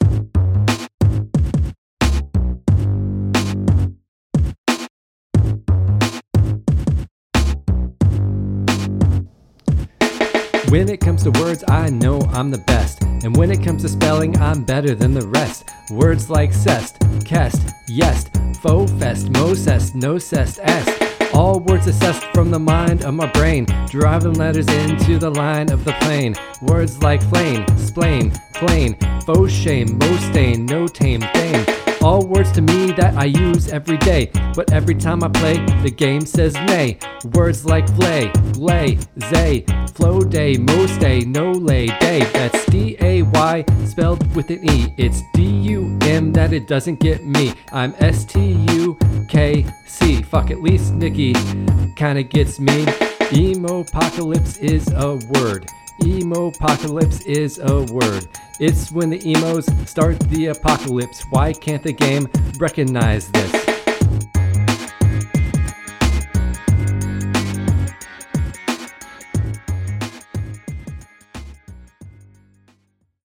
Words-with-Friends-Rap.mp3